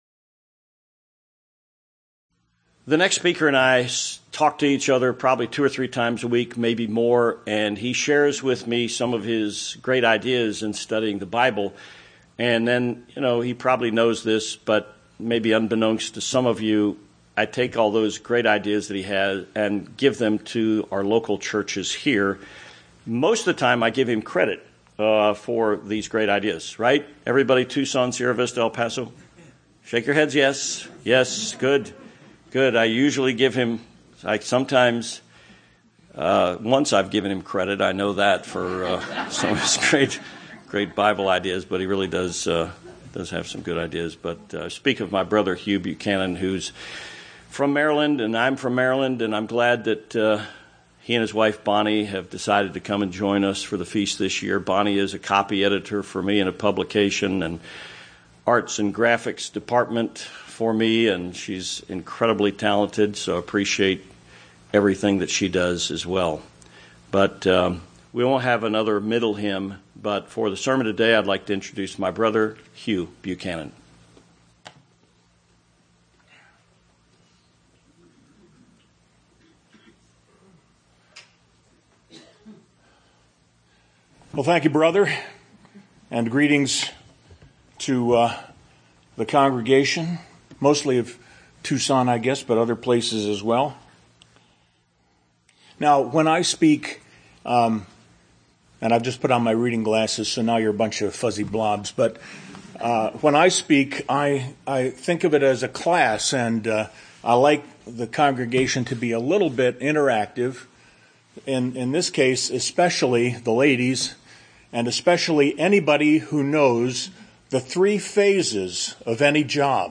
Sermons
Given in Tucson, AZ